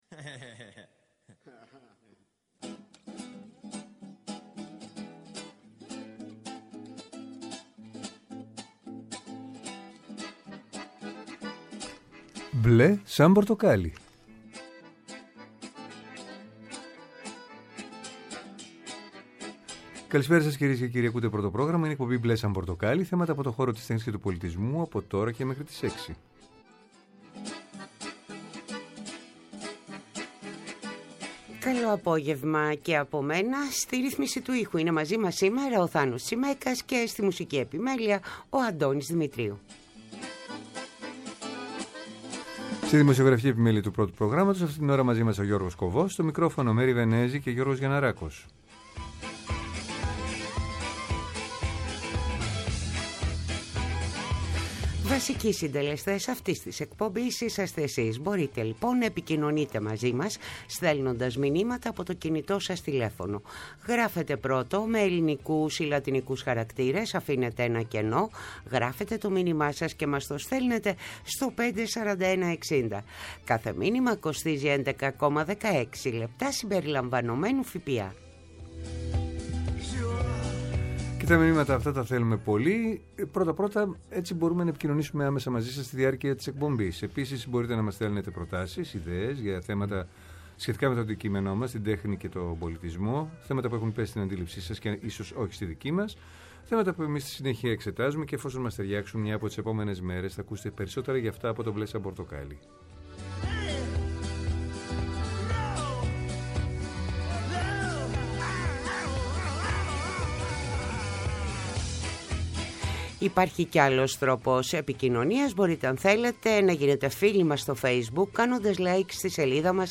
“Μπλε σαν πορτοκάλι”. Θέατρο, κινηματογράφος, μουσική, χορός, εικαστικά, βιβλίο, κόμικς, αρχαιολογία, φιλοσοφία, αισθητική και ό,τι άλλο μπορεί να είναι τέχνη και πολιτισμός, καθημερινά από Δευτέρα έως Πέμπτη 5-6 το απόγευμα από το Πρώτο Πρόγραμμα. Μια εκπομπή με εκλεκτούς καλεσμένους, άποψη και επαφή με την επικαιρότητα.